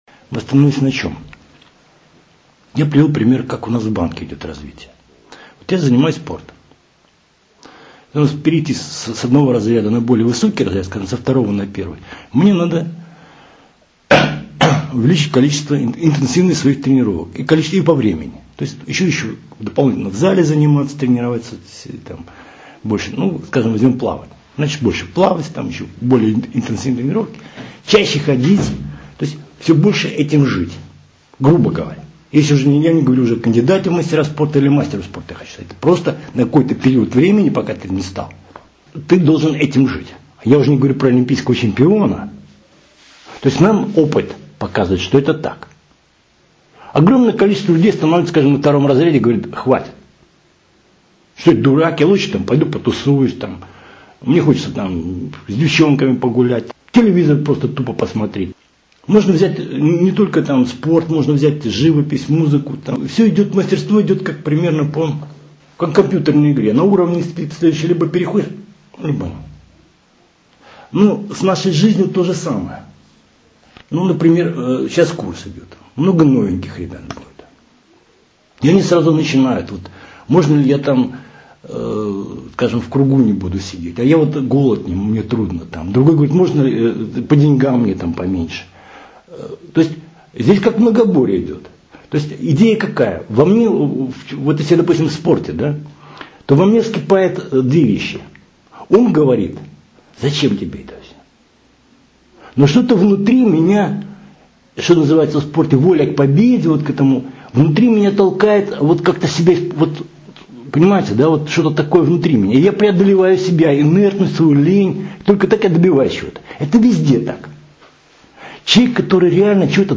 Часть 1 слушать беседу Однажды исследователи поместили колонию блох в банку с закрытой крышкой.